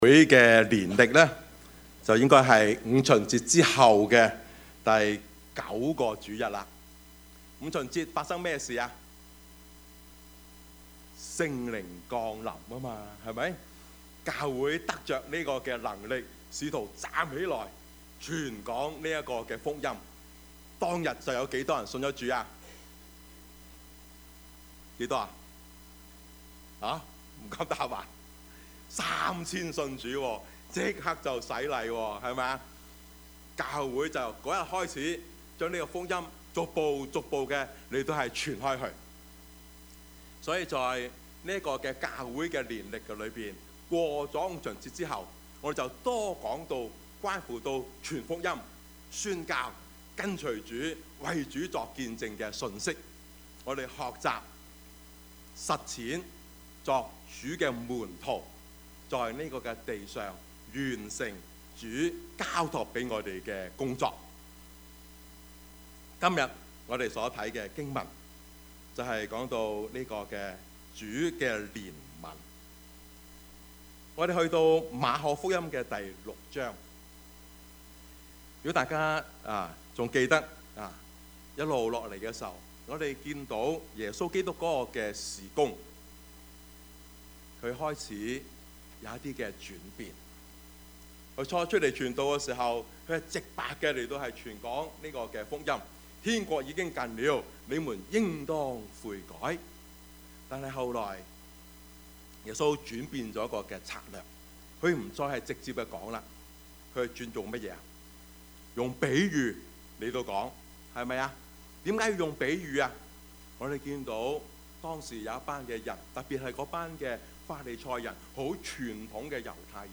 Service Type: 主日崇拜
Topics: 主日證道 « 生之無奈 務要傳道 »